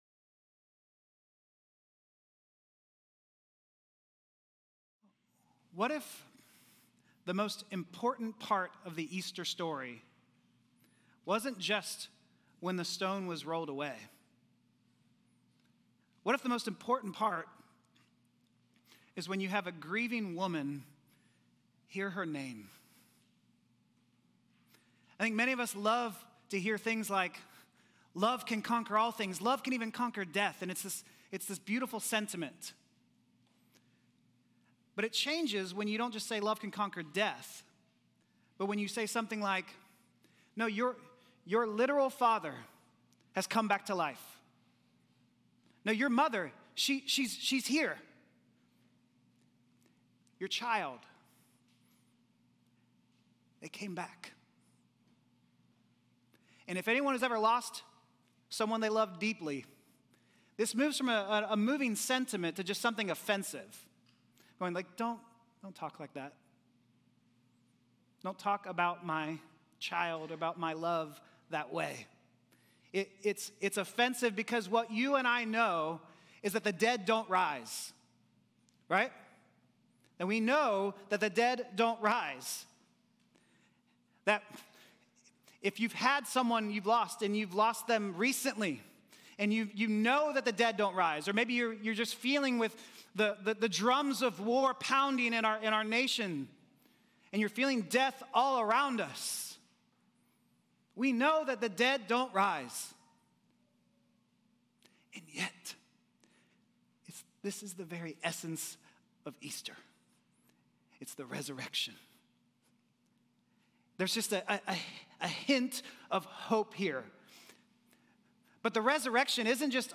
9AM Service Apr 5th 2026